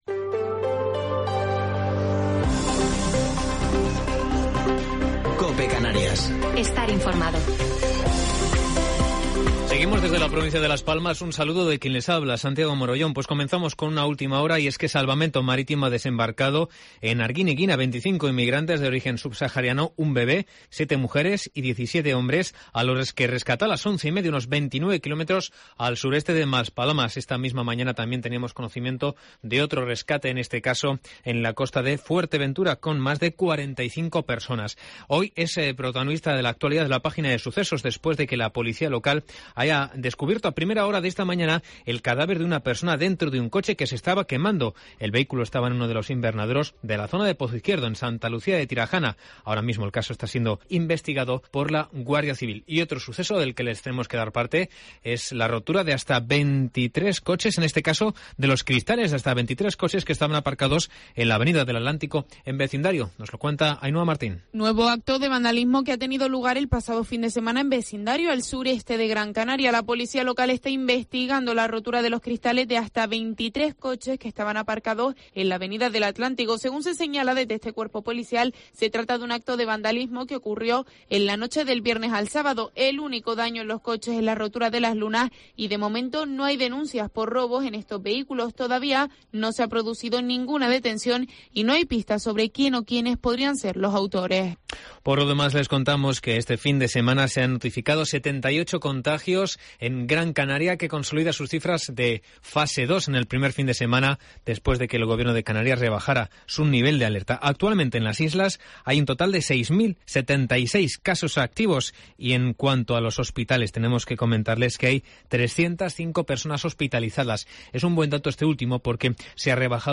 AUDIO: Informativo local 13 de septiembre de 2021